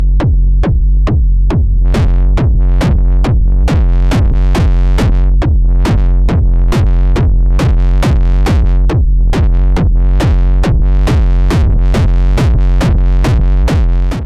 same as before, but +FX output into the input of the MK2 lol … who recognizes this song? :sweat_smile:
Sounds like a slow version of pitch hiker.